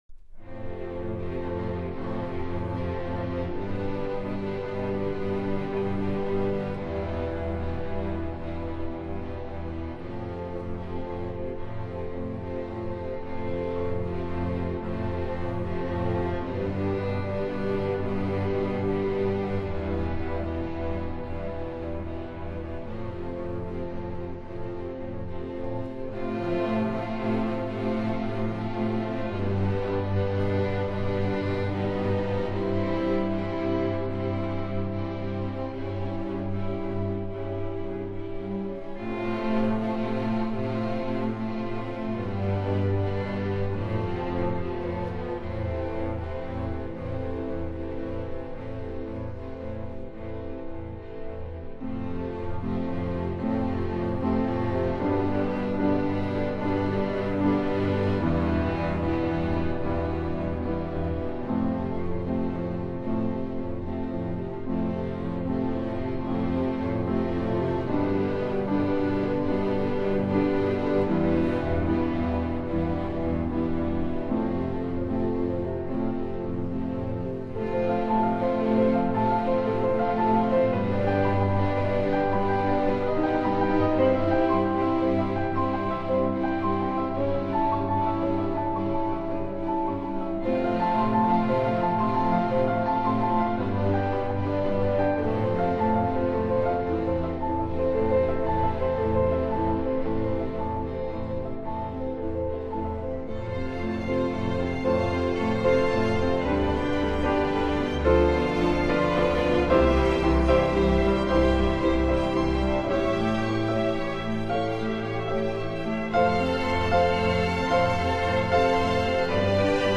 樂器： 小提琴
violin & conductor